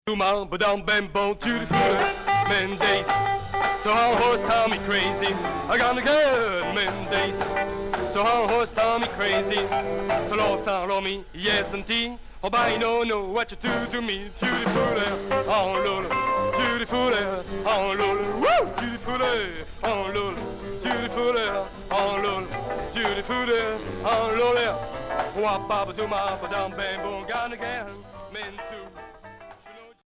Twist